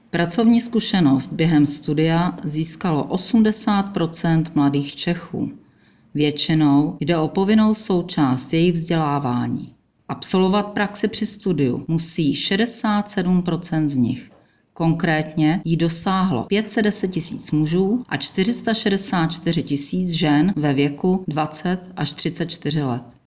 Vyjádření předsedkyně ČSÚ Ivy Ritschelové , soubor ve formátu MP3, 911.27 kB